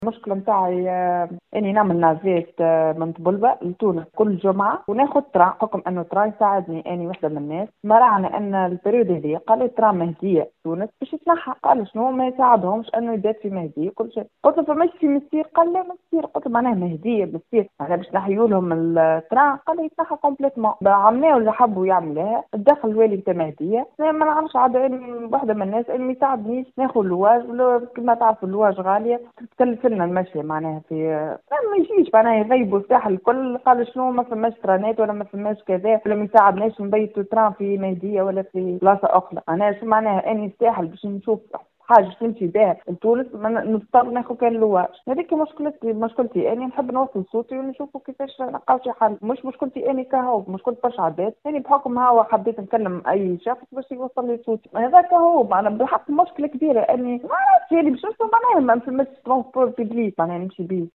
وجهت مواطنة نداء عاجلا على خلفية إعلامها بإمكانية إلغاء قطار المهدية تونس في فترة الصيف.